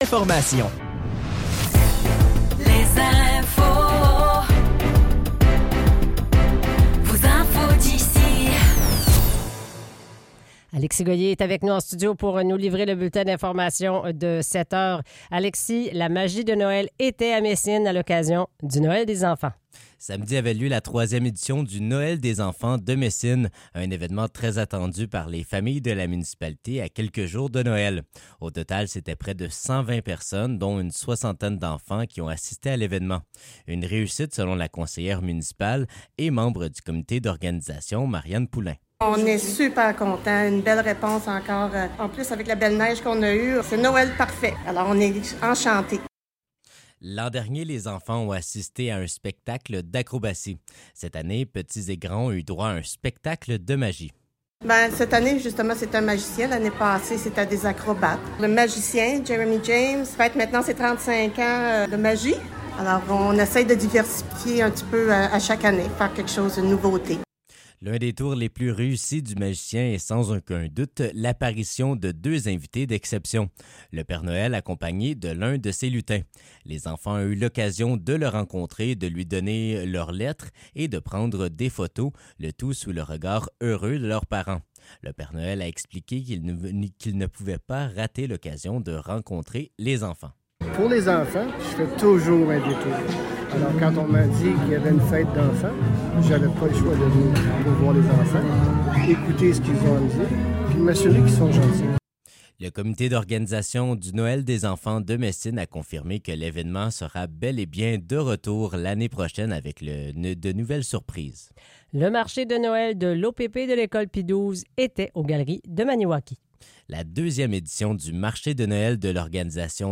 Nouvelles locales - 9 décembre 2024 - 7 h